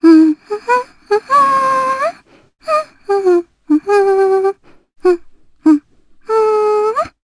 Cassandra-Vox_Hum_jp.wav